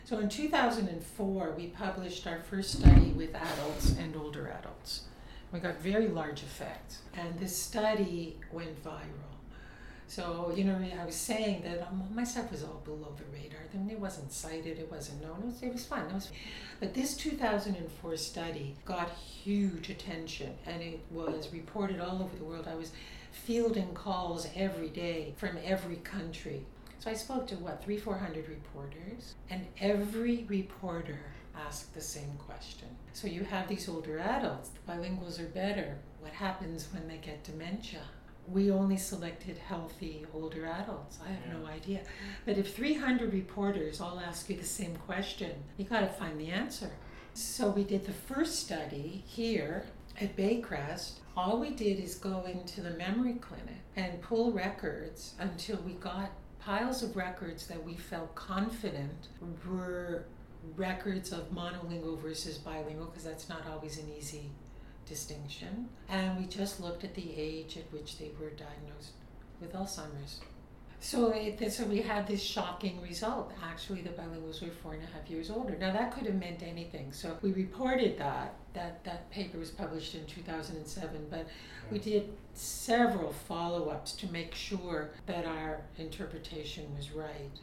In meeting with me she prefaced the conversation (and the recording I took) with the following consideration: her vocal tract was degraded by a health issue, and is acoustically different than what she grew up with.